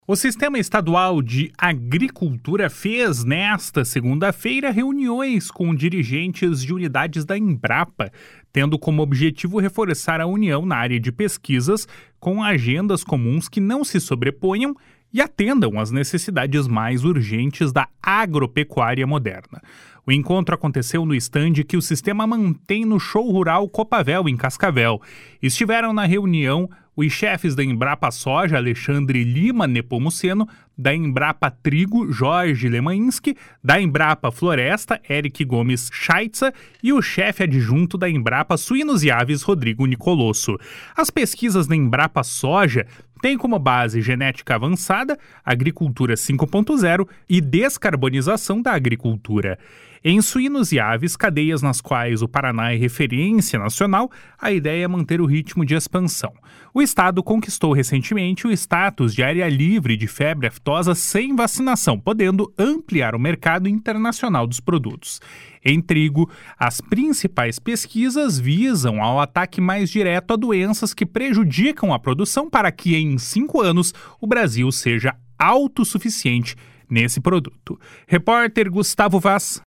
O encontro aconteceu no estande que o Sistema mantém no Show Rural Coopavel, em Cascavel.
Em trigo, as principais pesquisas visam ao ataque mais direto a doenças que prejudicam a produção para que em cinco anos o Brasil seja autossuficiente nesse produto. (Repórter